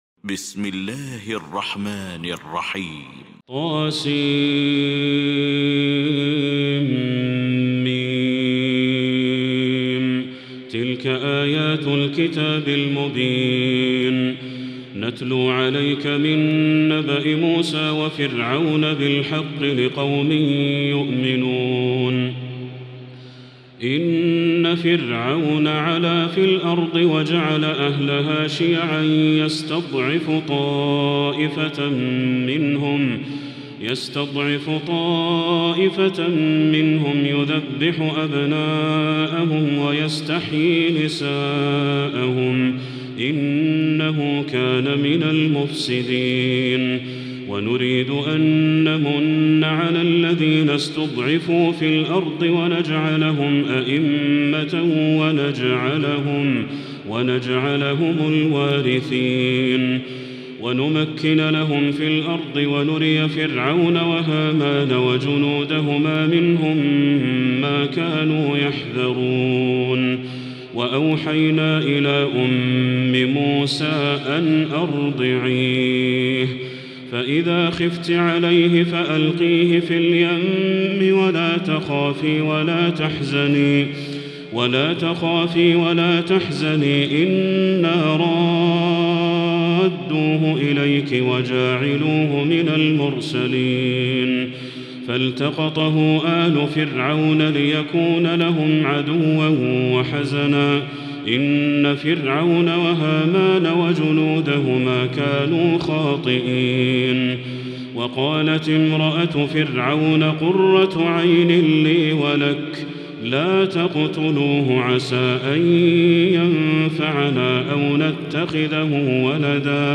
المكان: المسجد الحرام الشيخ